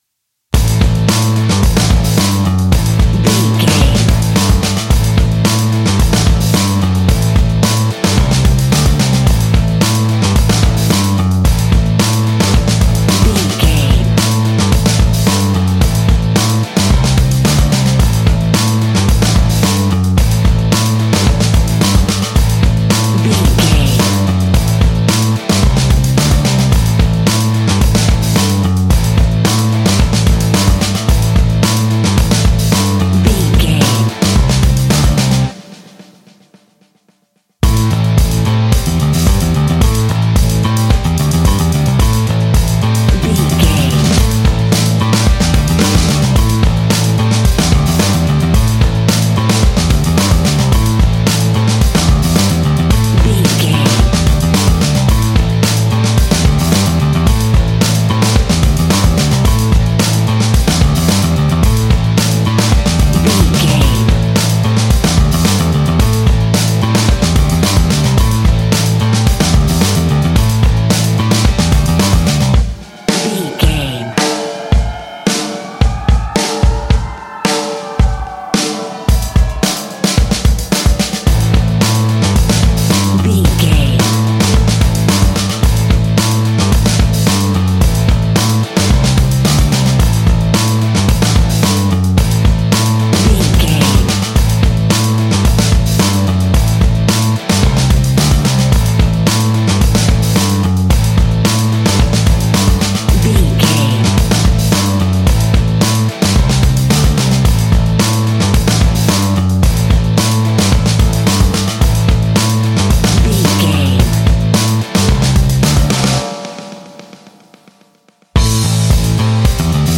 Ionian/Major
Fast
energetic
driving
happy
bright
electric guitar
bass guitar
drums
hard rock
heavy metal
blues rock
distortion
heavy drums
distorted guitars
hammond organ